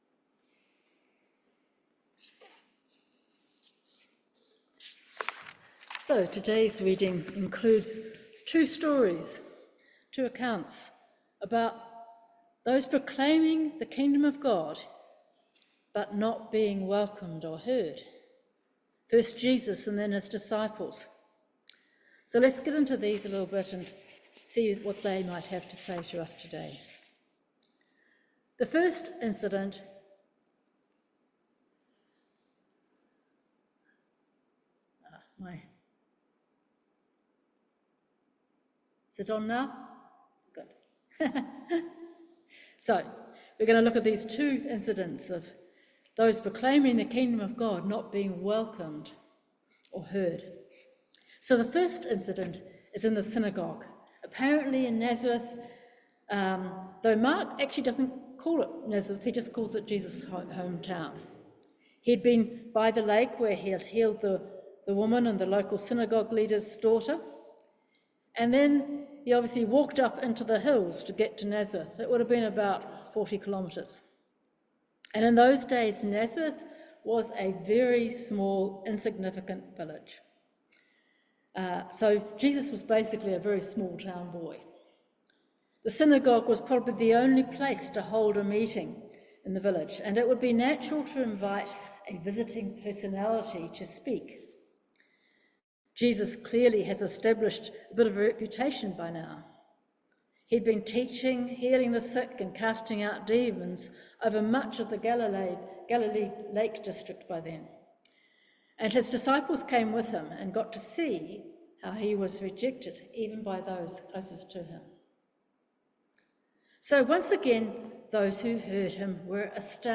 Mark 6:1-13 Service Type: Holy Communion If we seek to follow Jesus